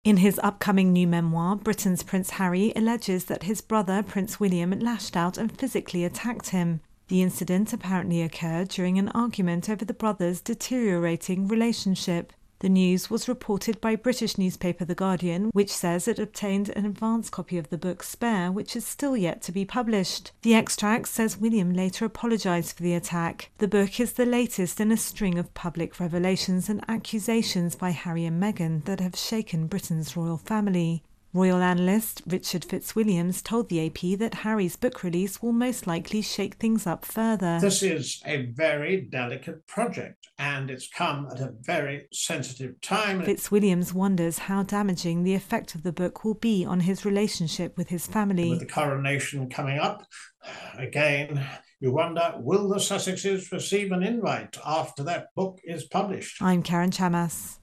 Report: Prince Harry says William attacked him during row